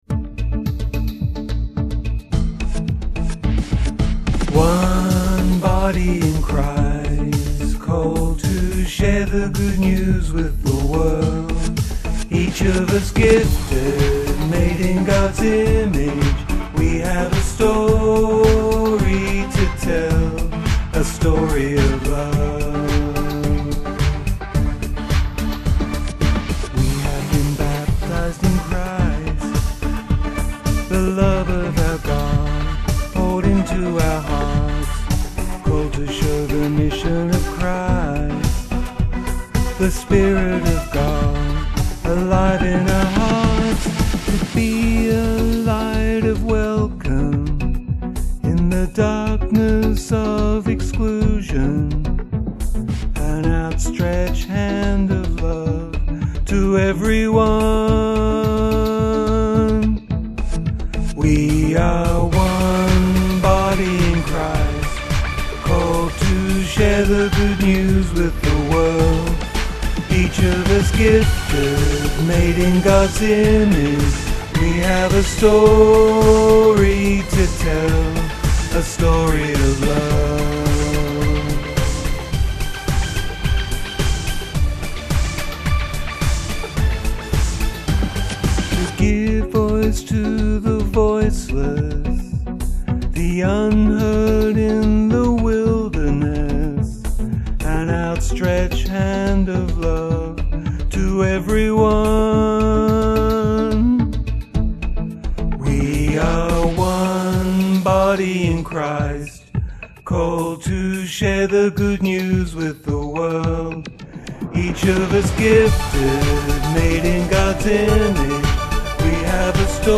Just for fun I made a dance version with my lousy singing.